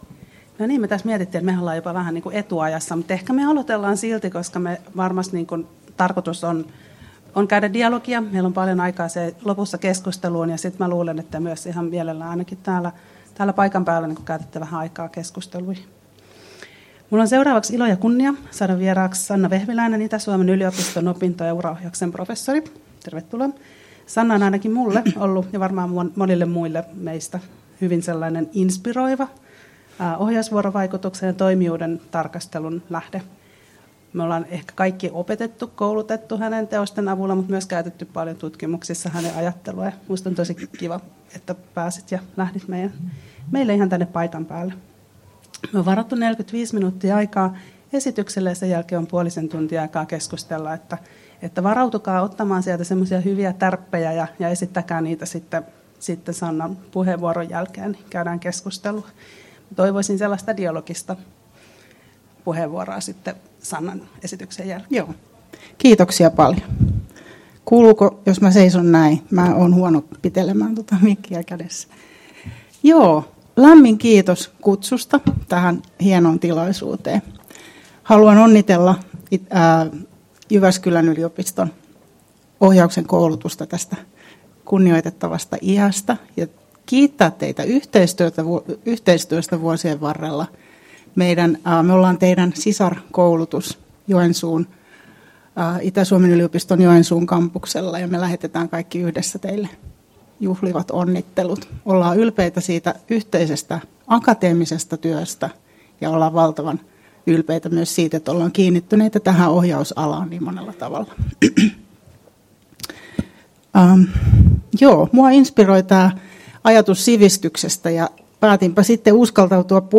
Tallenne 17.11.2023: Ohjausalan koulutus opettajankoulutuksessa 50-vuotta juhlaseminaari